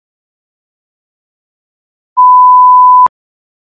(Pour écouter les sons à différentes fréquences ci-dessous, il suffit de cliquer sur celui que vous souhaitez entendre)
1 kHz] [